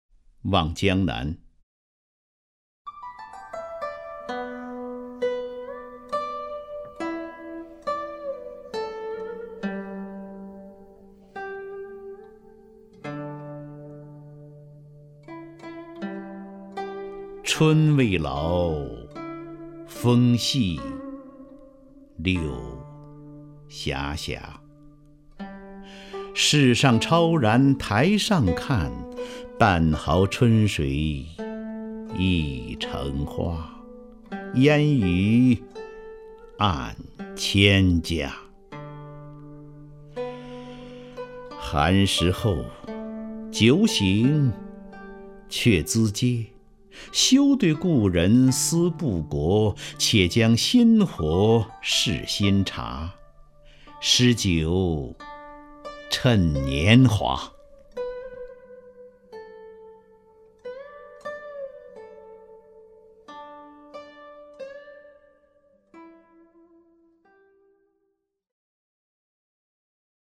张家声朗诵：《望江南·春未老》(（北宋）苏轼)
名家朗诵欣赏 张家声 目录